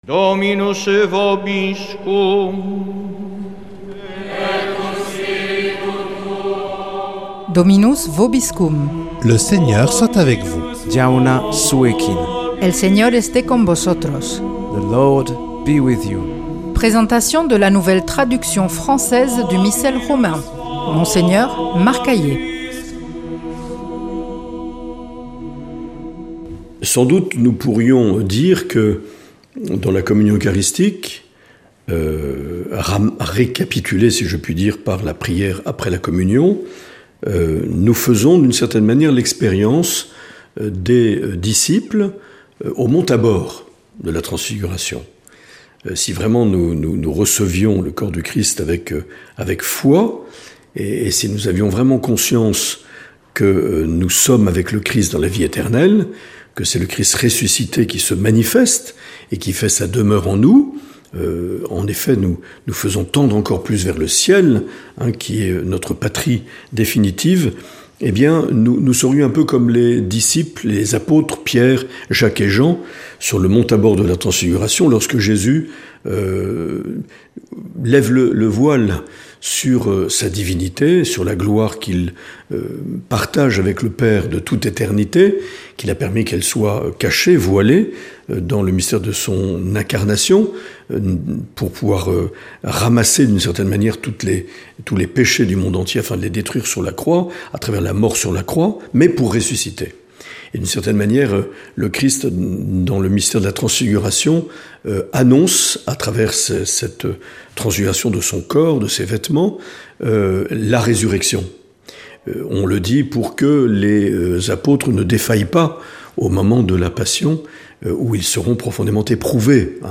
Présentation de la nouvelle traduction française du Missel Romain par Mgr Marc Aillet
Monseigneur Marc Aillet